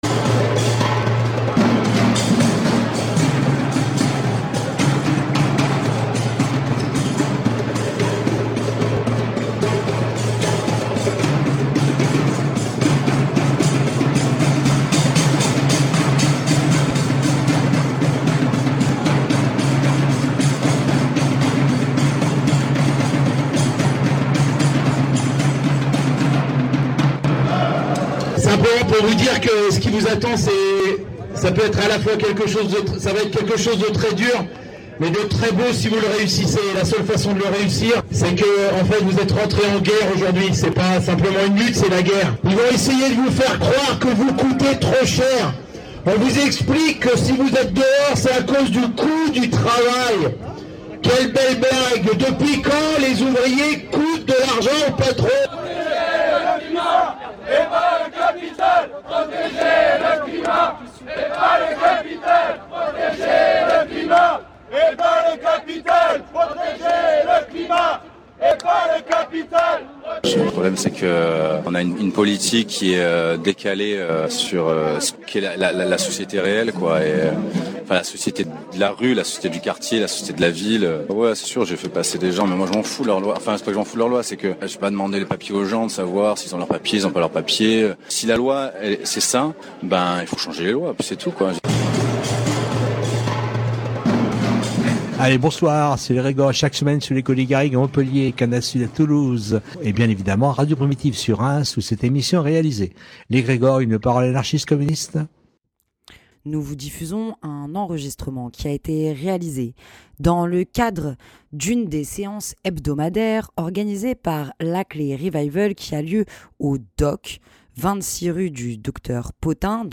Dans cette émission, nous vous diffusons des extraits des enregistrements réalisés lors de cette soirée. classé dans : société Derniers podcasts Découvrez le Conservatoire à rayonnement régional de Reims autrement !